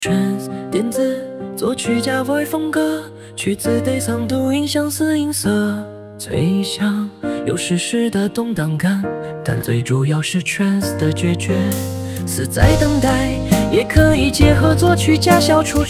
trance，电子
脆响，有史诗的动荡感，但最主要是trance的决绝，似在等待